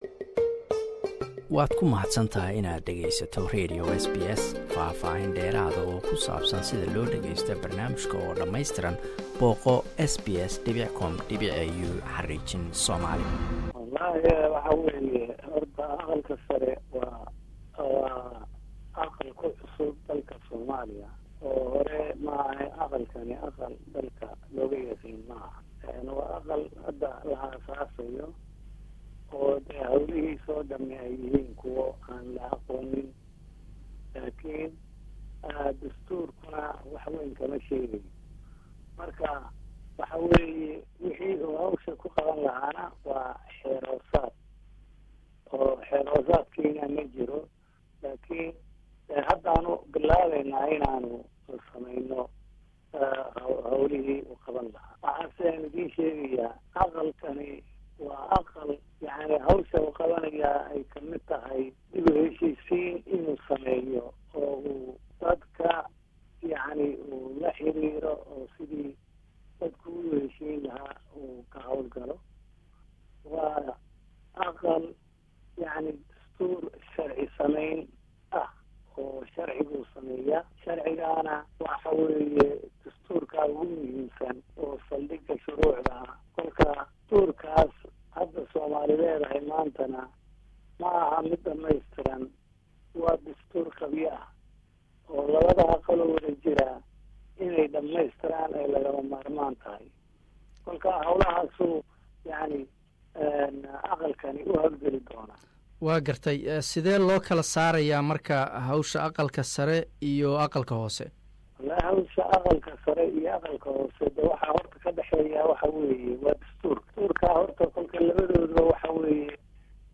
Interview: Abdi Hashi, speaker of upper house of Somali parliament.
Abdi hashi is the speaker of newly established upper house of Parliament in Somalia. He spoke to SBS Somali about the role of this new institution.